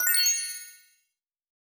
Coins (26).wav